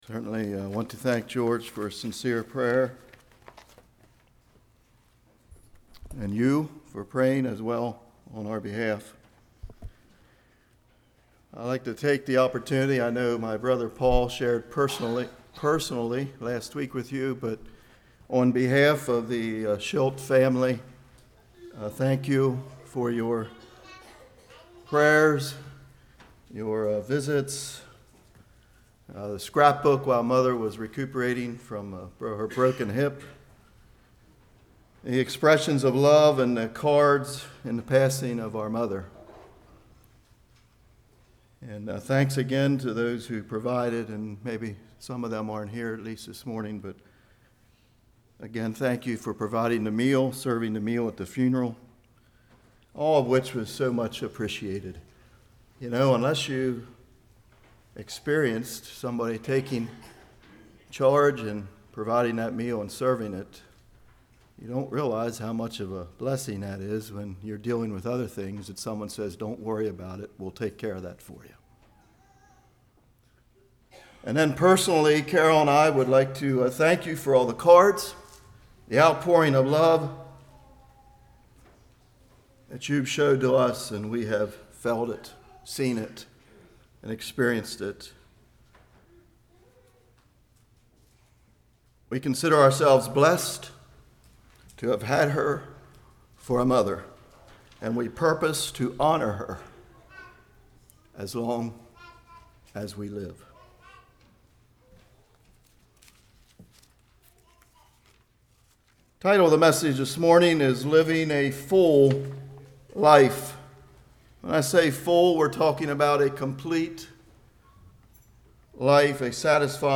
John 21:15-22 Service Type: Morning Regrets Freedom Living Beyond Regrets « Pure Heart